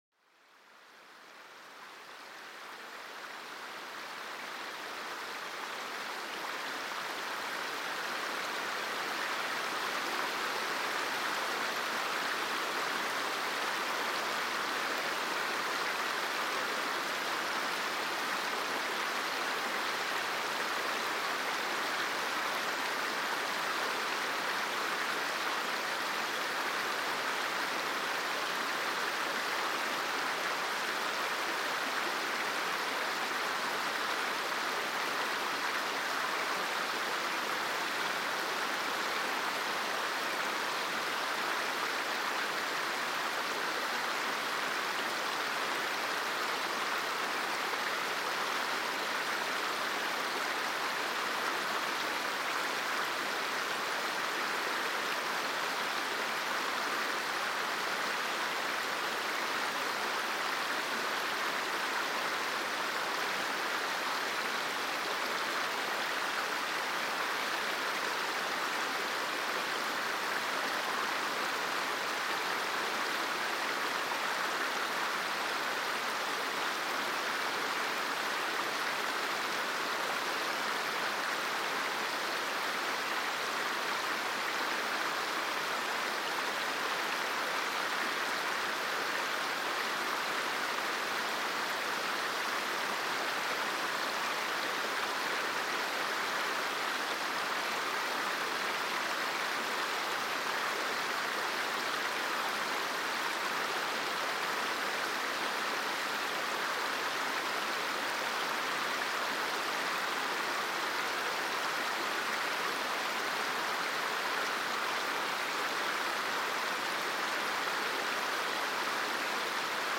El suave murmullo de un río para calmar la mente